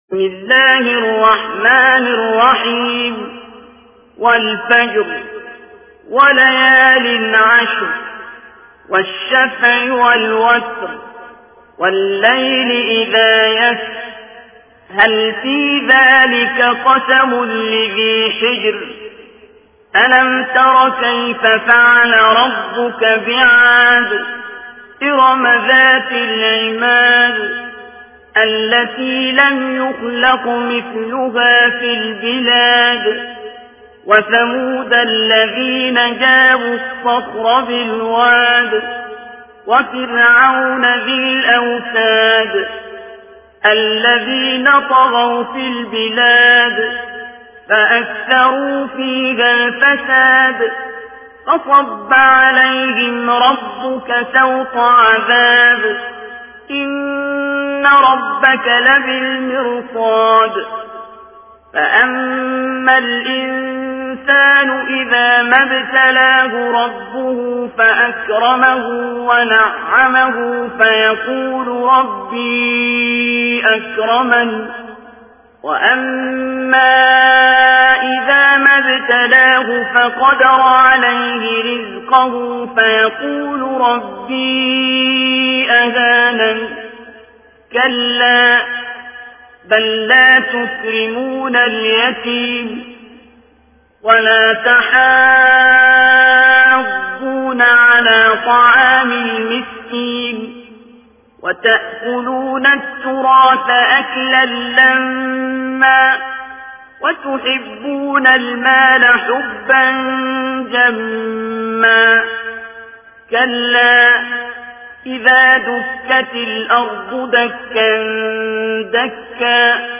Tarteel Recitation of Surah Al-Fajr by Abdul Basit
TEHRAN (IQNA) – What follows is the recitation of Surah al-Fajr by late prominent qari Sheikh Abdul Basit Abdul Samad.